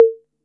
TR 808 Conga 02.wav